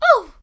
peach_yah_wah_hoo1.ogg